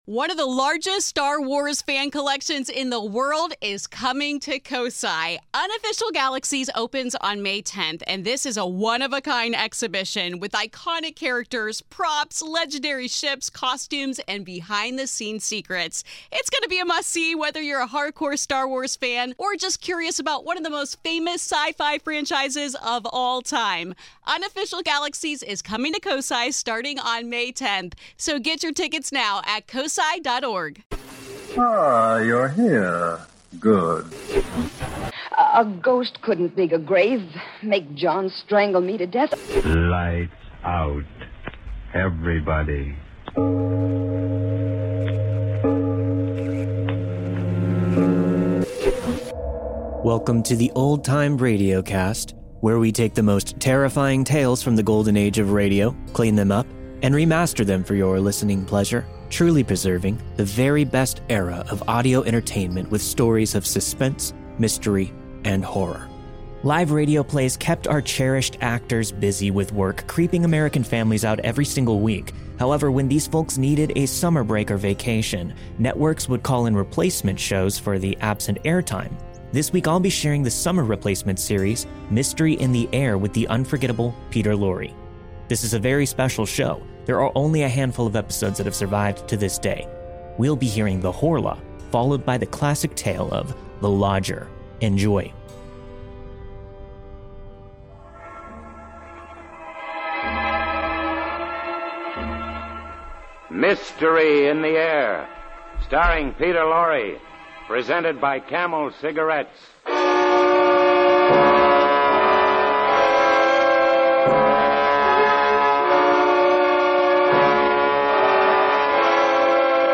On this week's episode of the Old Time Radiocast we present you with two stories from the classic radio program Mystery in the Air with Peter Lorre.